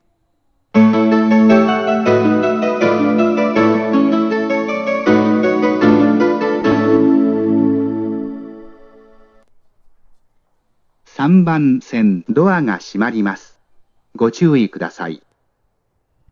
接近放送 「ムーンストーン」です。
●音質：良